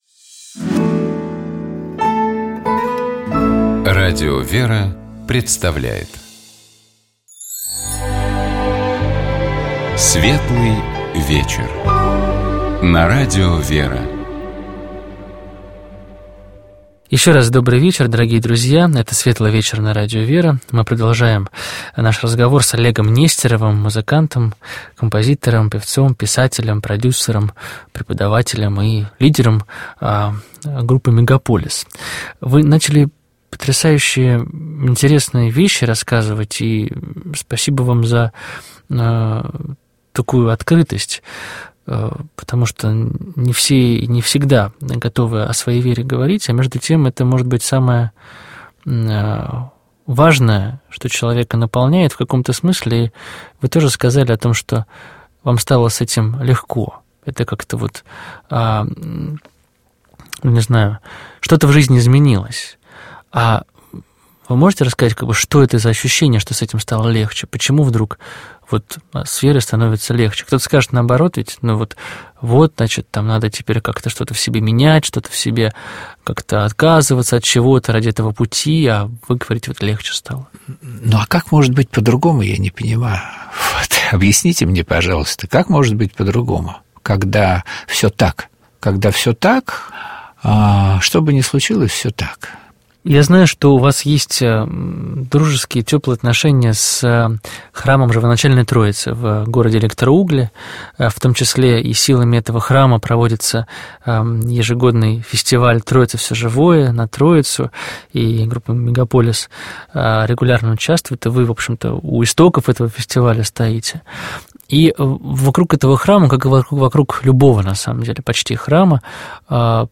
У нас в гостях был музыкант, лидер группы «Мегаполис» Олег Нестеров.